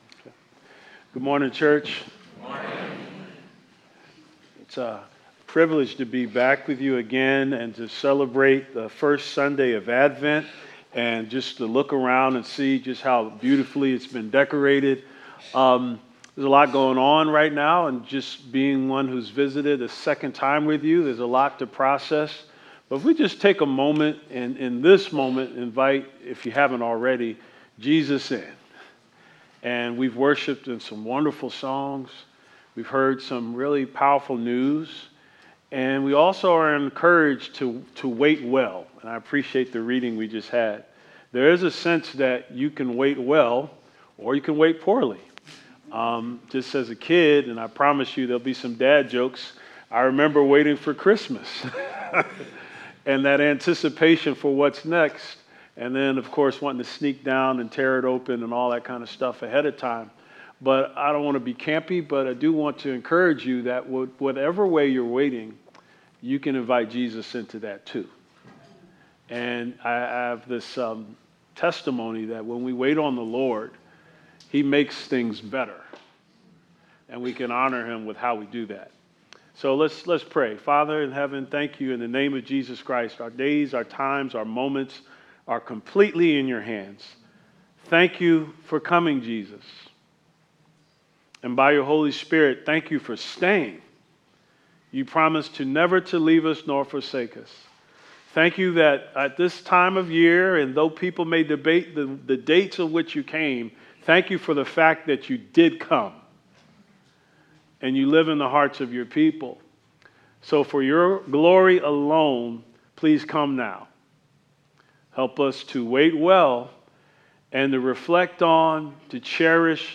Luke 2 Service Type: Sunday Is there such a thing as “good news” in a world of bad news?